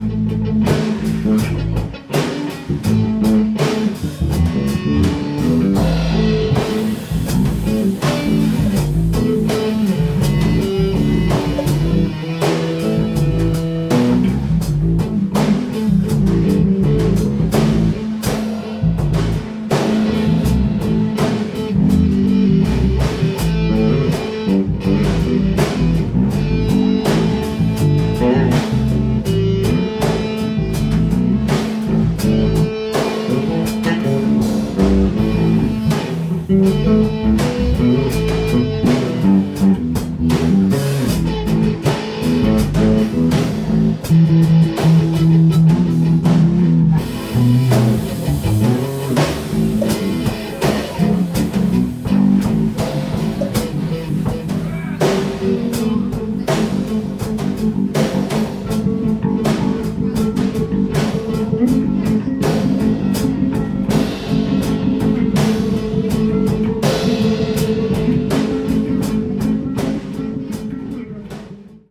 05-nice guitar riff [ 1:12 ] Play Now | Play in Popup | Download
05-nice-guitar-riff.mp3